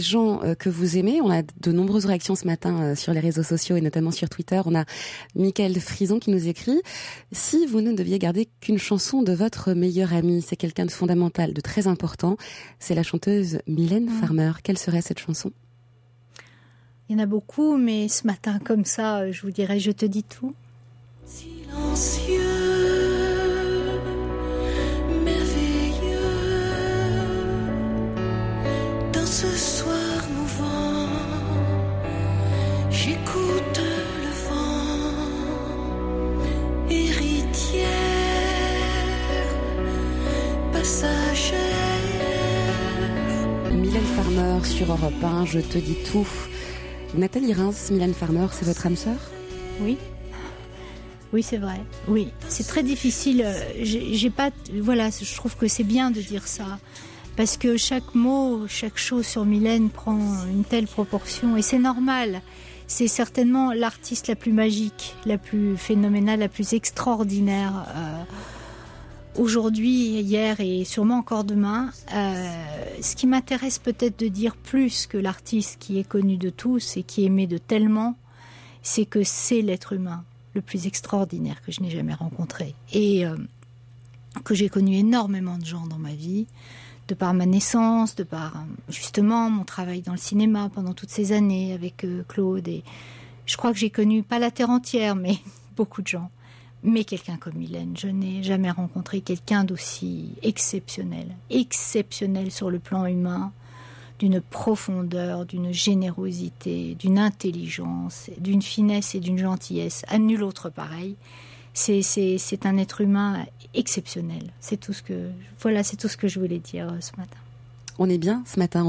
Interview de Nathalie Rheims